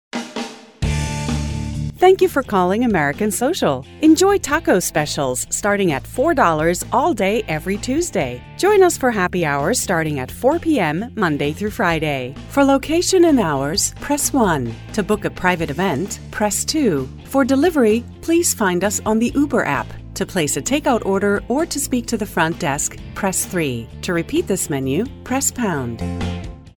Auto attendant IVR professional music and messages recordings for telephone systems